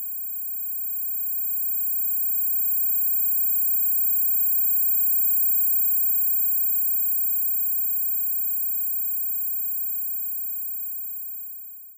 tinnitusx.ogg